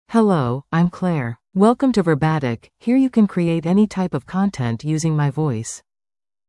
ClaireFemale English AI voice
Claire is a female AI voice for English (United States).
Voice sample
Listen to Claire's female English voice.
Claire delivers clear pronunciation with authentic United States English intonation, making your content sound professionally produced.